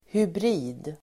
Uttal: [hybr'i:d]